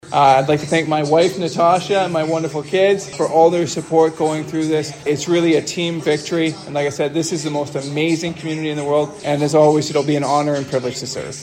Speaking after his win, Lawrence thanked voters for their support and said he was eager to continue advocating for local residents.